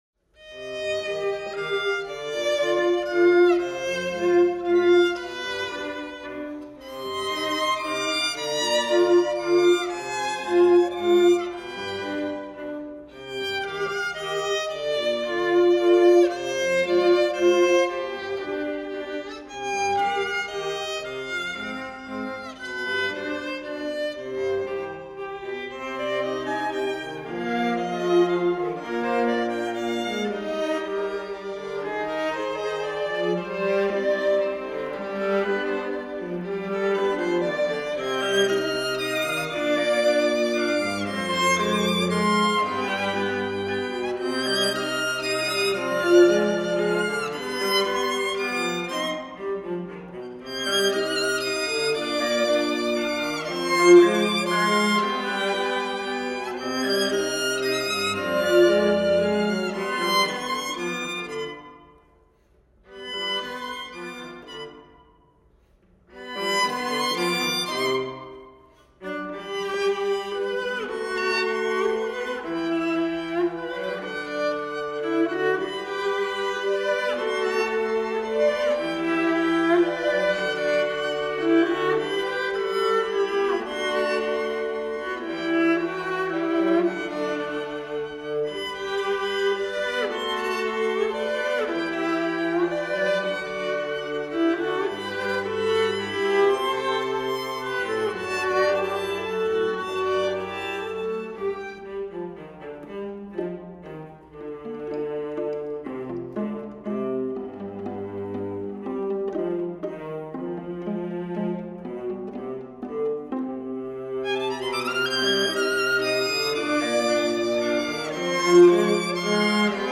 String Quartet No. 2